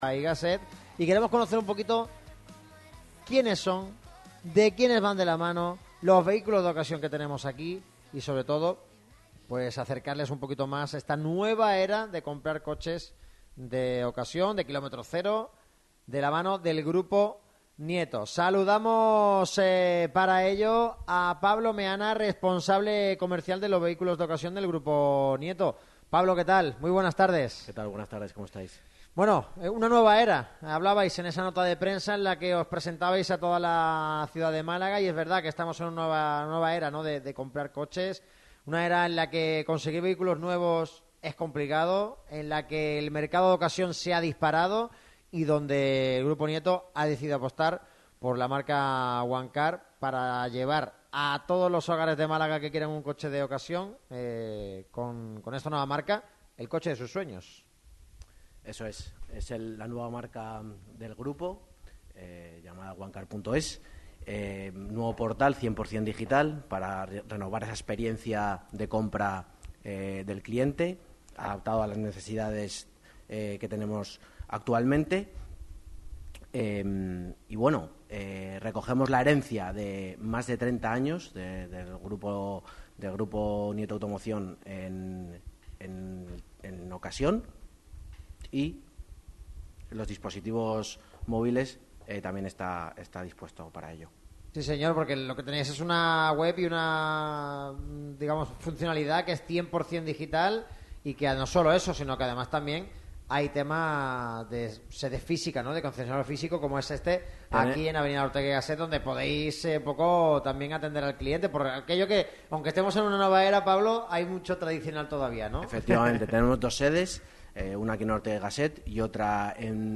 La radio del deporte malagueño se desplaza hasta la Avenida Ortega y Gasset 294, a una de las sedes de Onecar.